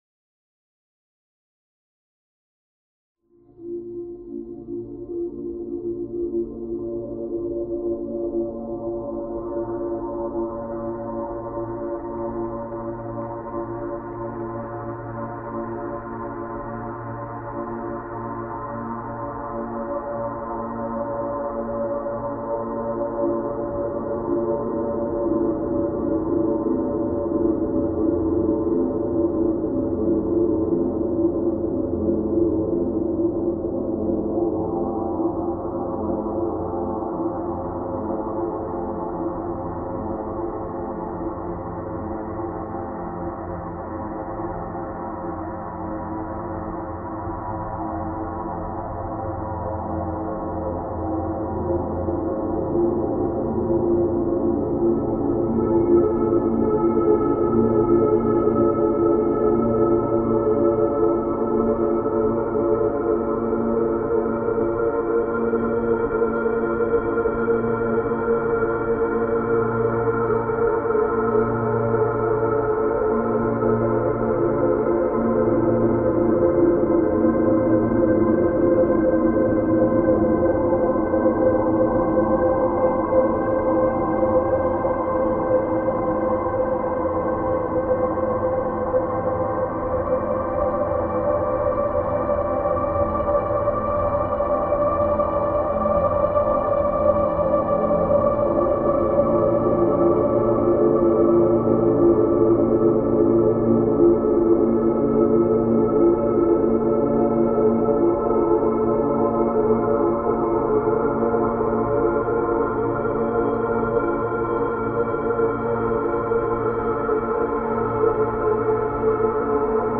File under: Ambient / Dark Ambient